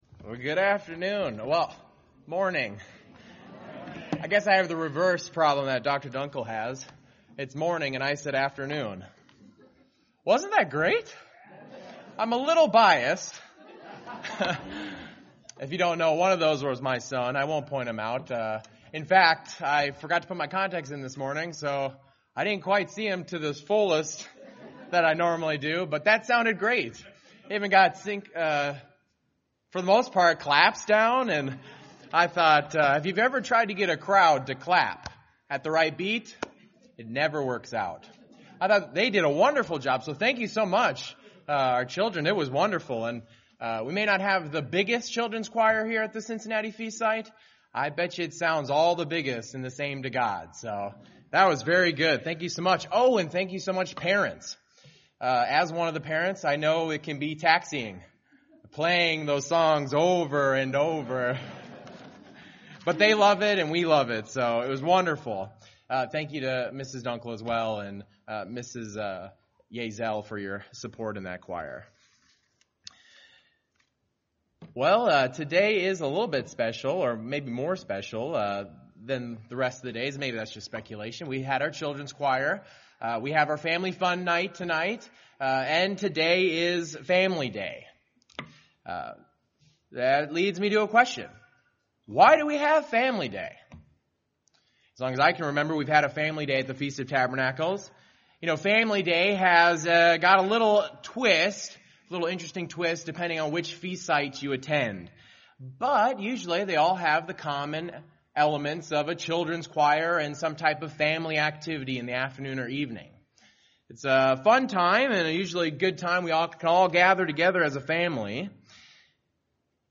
This sermon was given at the Cincinnati, Ohio 2019 Feast site.